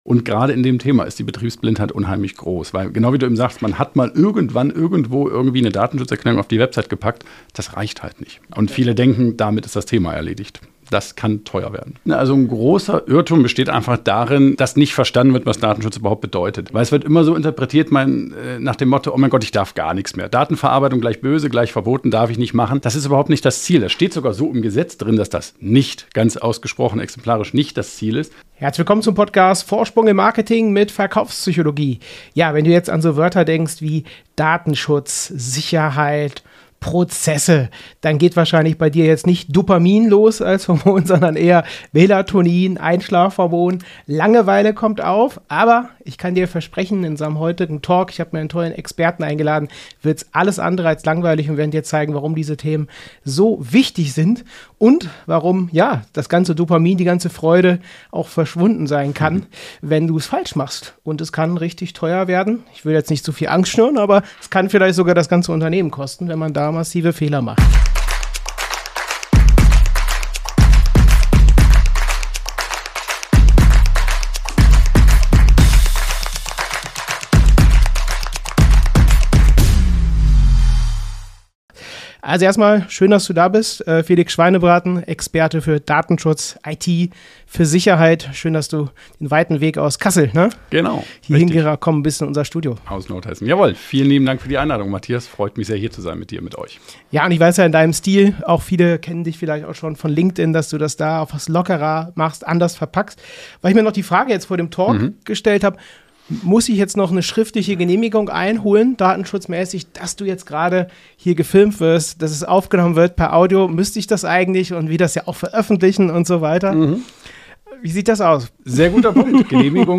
Datenschutz: Lästiges Übel oder unterschätzter Erfolgsfaktor? Talk